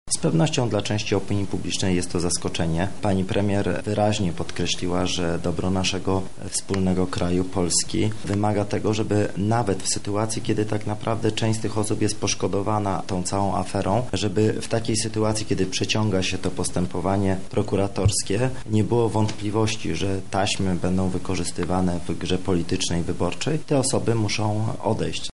Minister sprawiedliwości skomentował również wczorajsze zmiany w polskim rządzie: